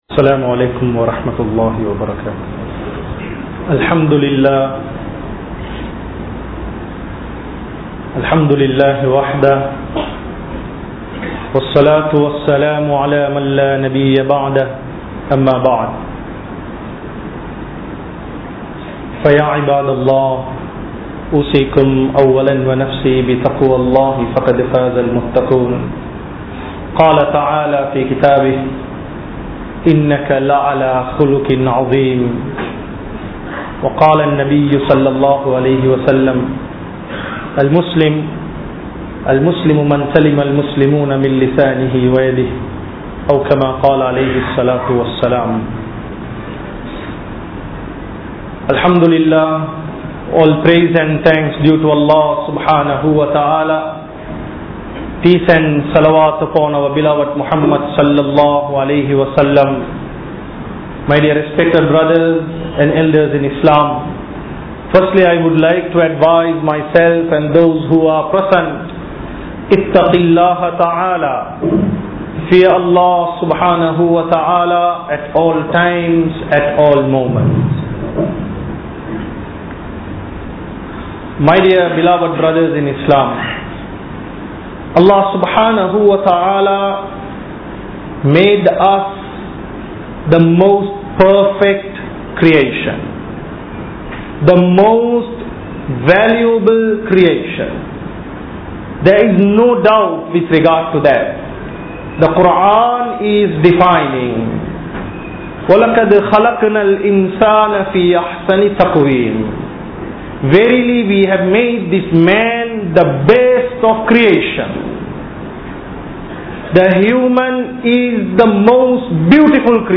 Ahlaaq (Good Qualities) | Audio Bayans | All Ceylon Muslim Youth Community | Addalaichenai
Thaqwa Jumua Masjith